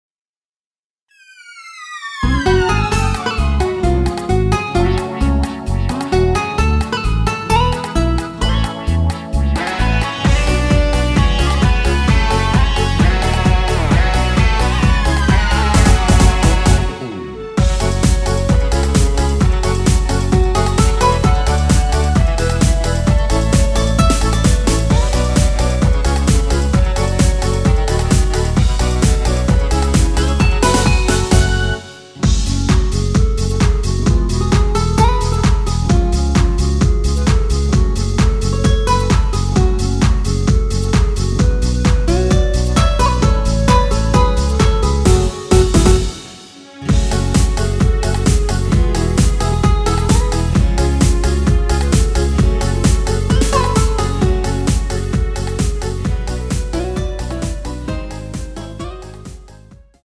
원키에서(-7)내린 멜로디 포함된 MR입니다.
F#m
앞부분30초, 뒷부분30초씩 편집해서 올려 드리고 있습니다.